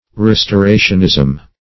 restorationism - definition of restorationism - synonyms, pronunciation, spelling from Free Dictionary
Search Result for " restorationism" : The Collaborative International Dictionary of English v.0.48: Restorationism \Res`to*ra"tion*ism\ (-?z'm), n. The belief or doctrines of the Restorationists.